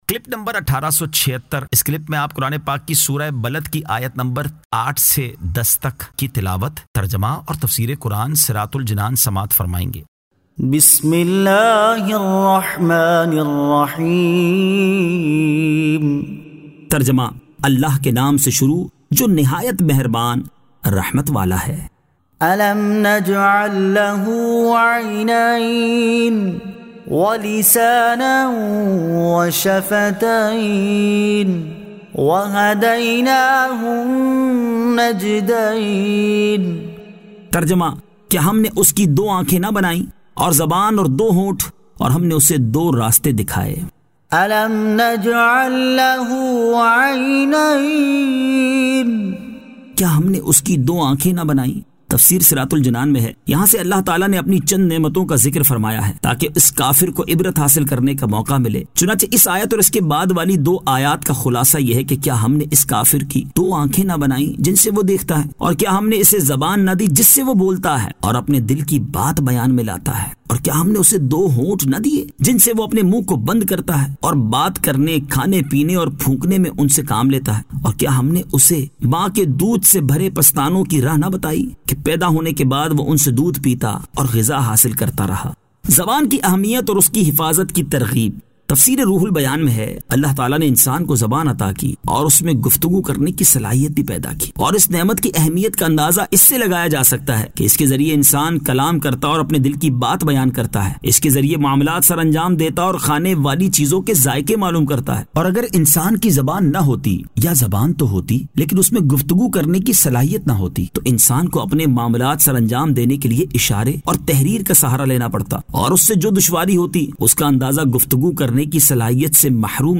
Surah Al-Balad 08 To 10 Tilawat , Tarjama , Tafseer